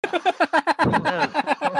risada